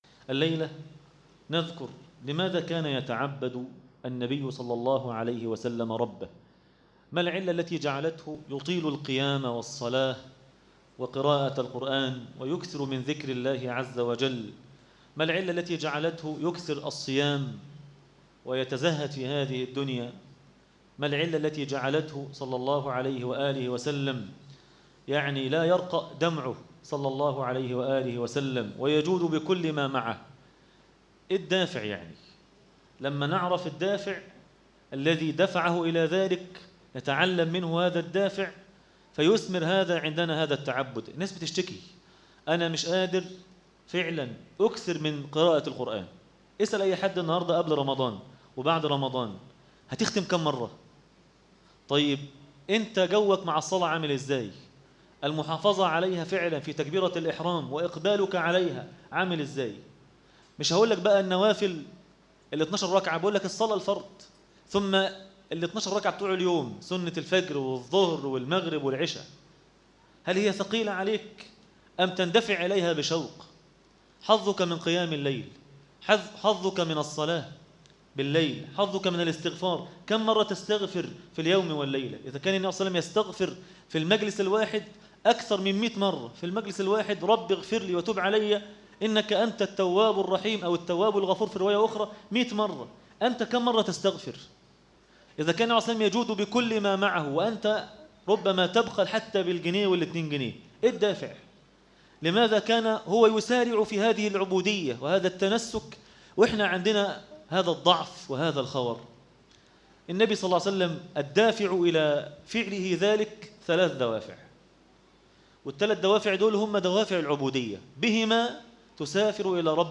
عنوان المادة النبي (صلي الله عليه وسلم ) عابدا - الجزء الثاني- درس التراويح ليلة 4 رمضان 1437هـ